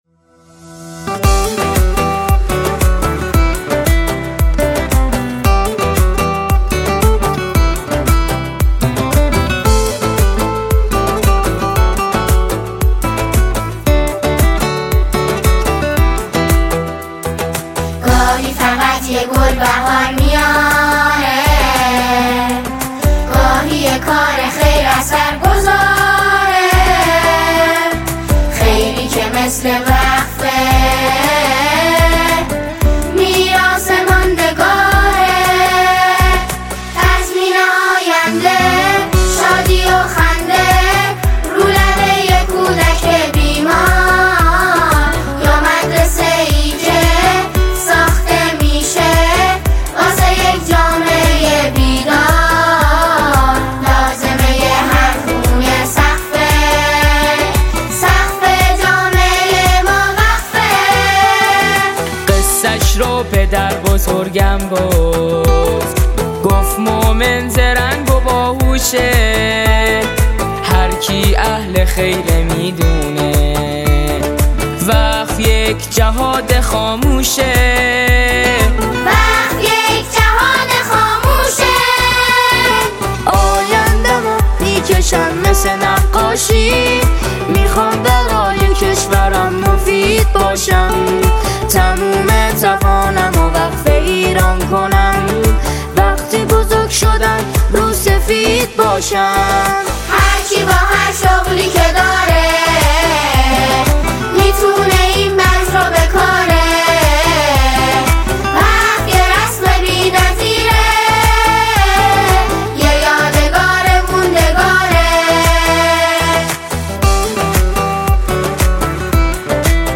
ژانر: سرود